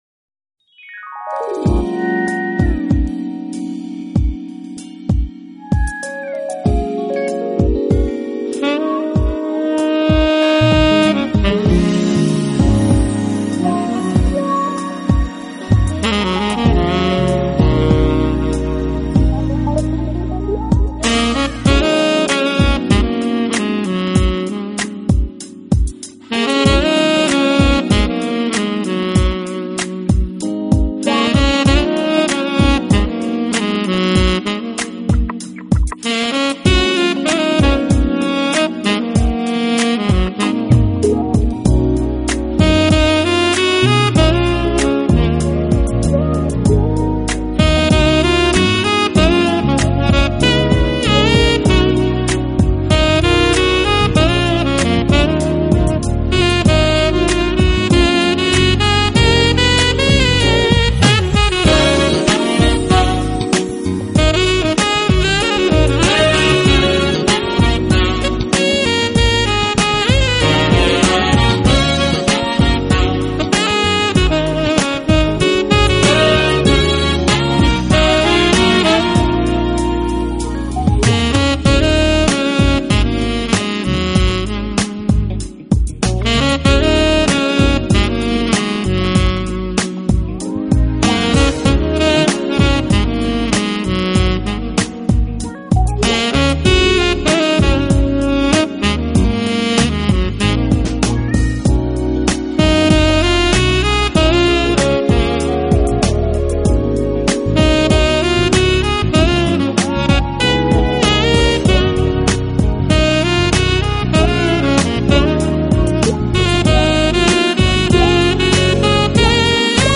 Genre: Smooth Jazz
sax and flute man